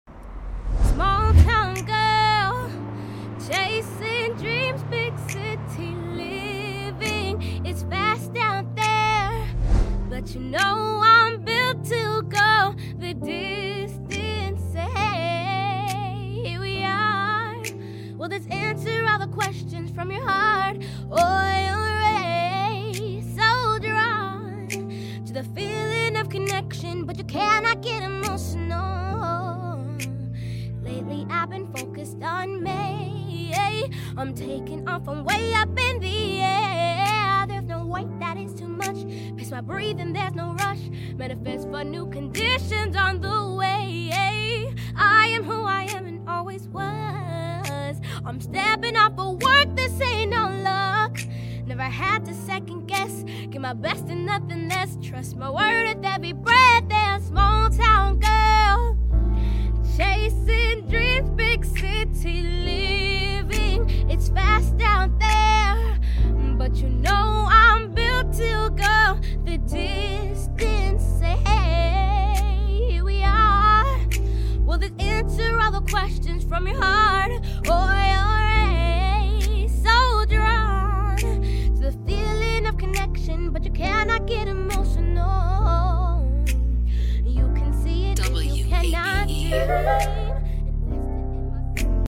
live performance